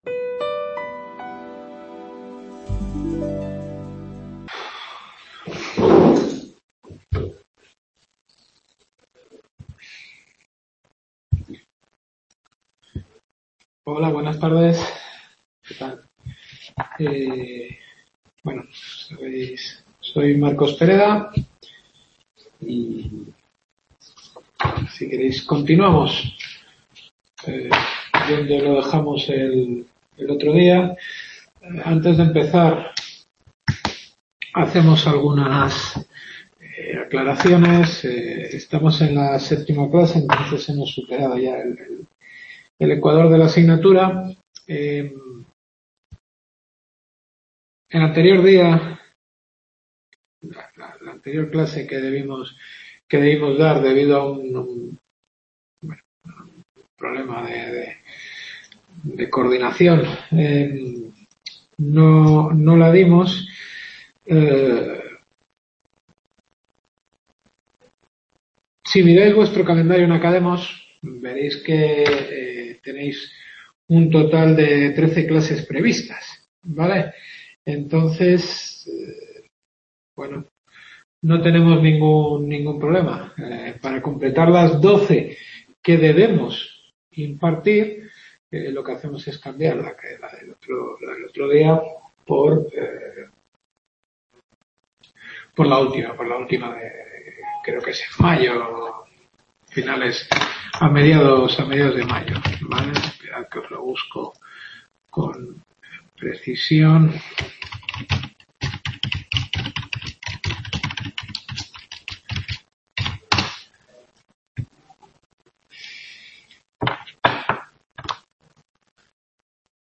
Séptima clase Description Derecho Administrativo Europeo.